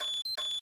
Key_Lock.ogg